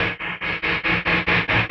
Index of /musicradar/rhythmic-inspiration-samples/140bpm